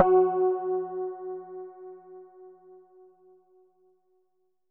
SPOOKY F#3.wav